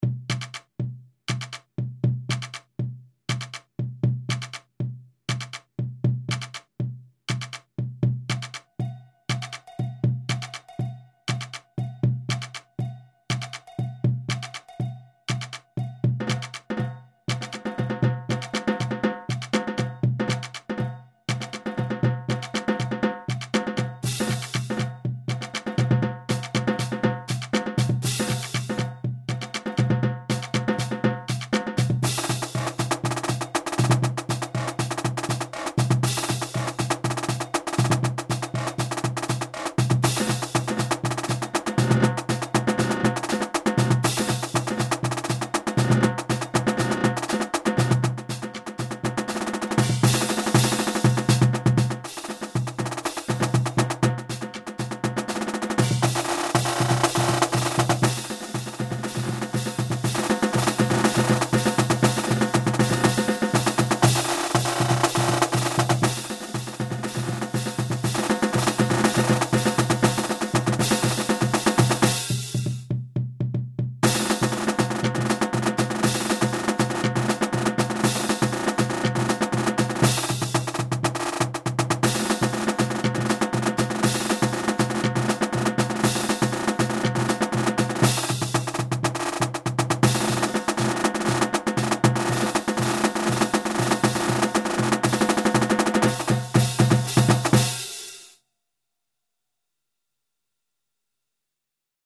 Ongestemd Marcherend Slagwerk
Snare drum Cowbell Cymbals Bass drum